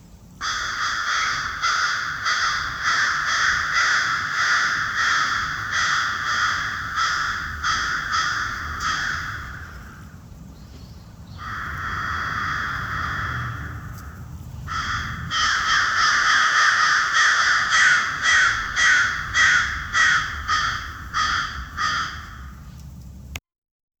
rapid crow noises
rapid-crow-noises-ju4lfczv.wav